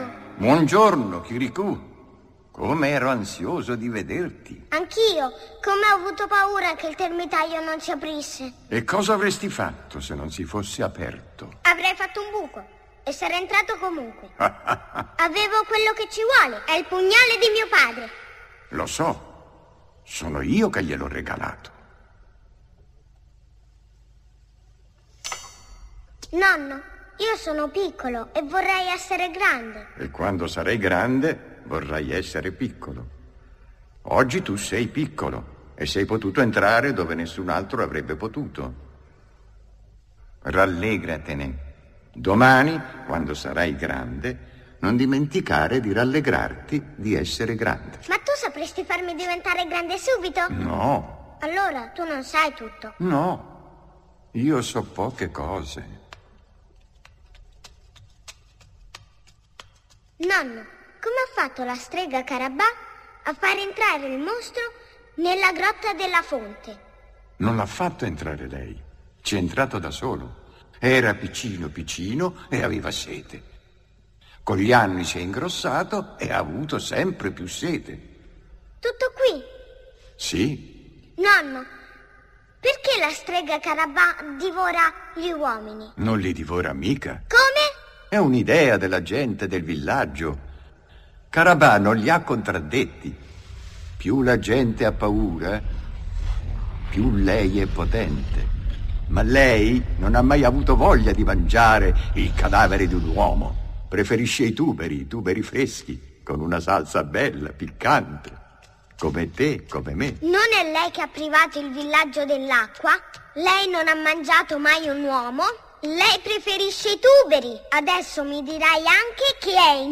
voce di Aroldo Tieri nel film d'animazione "Kirikù e la strega Karabà", in cui doppia il Grande Saggio.